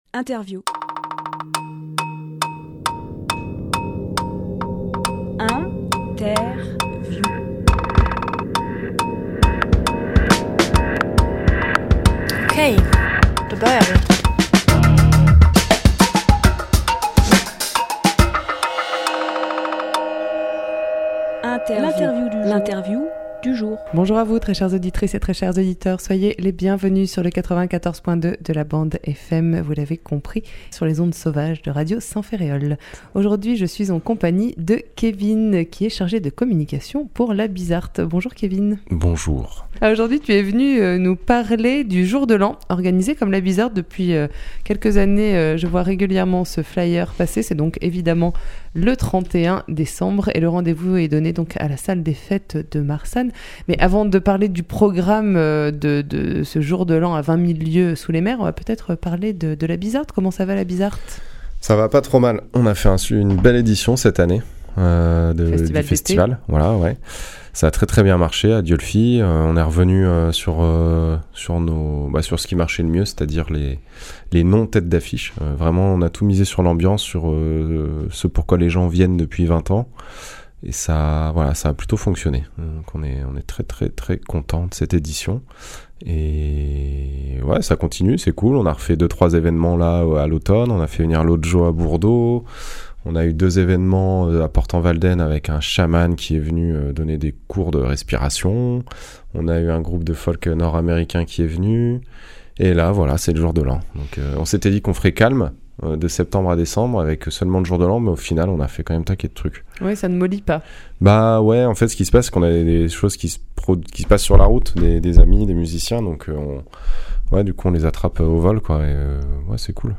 Emission - Interview Jour de l’An Bizzart Publié le 24 décembre 2018 Partager sur…